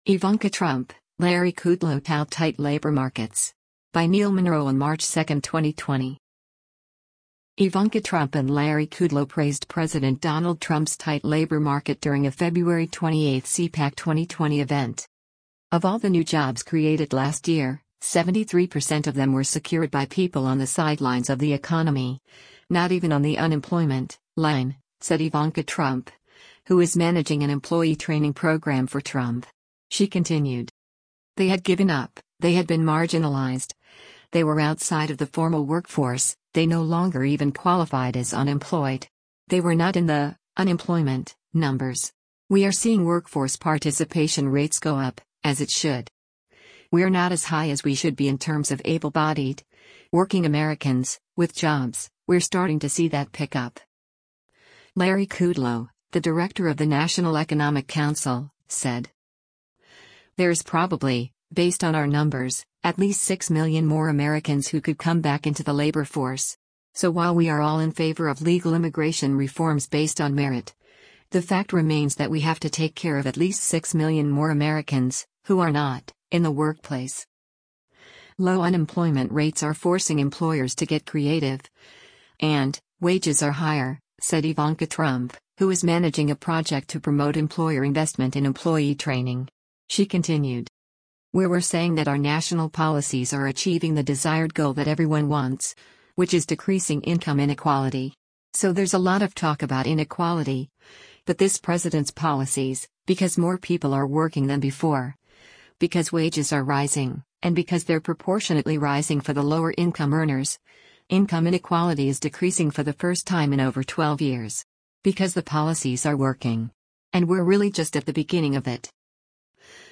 Ivanka Trump and Larry Kudlow praised President Donald Trump’s tight labor market during a February 28 CPAC 2020 event.